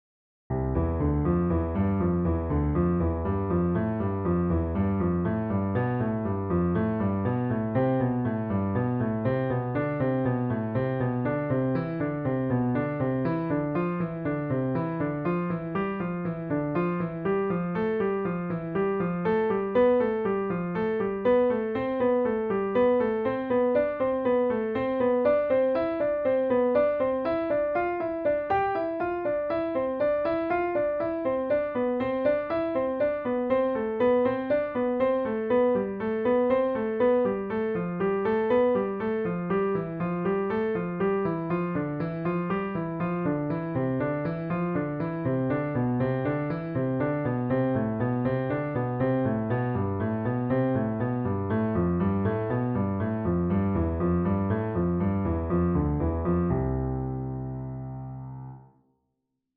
piano exercises
for Piano